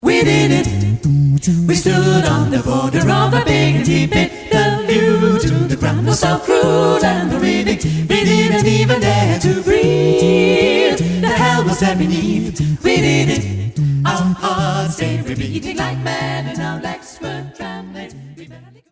As mp3 stereo files